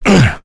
Kain-Vox_Damage_04.wav